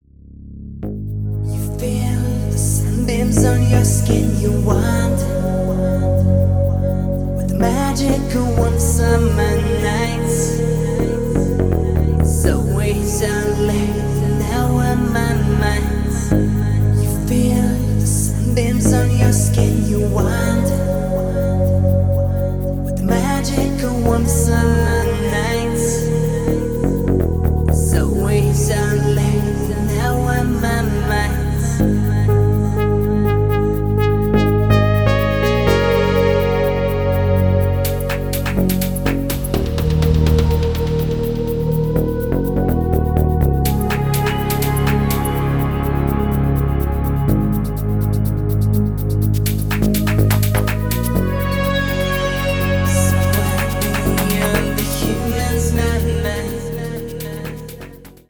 • Качество: 320, Stereo
Стиль: deep house.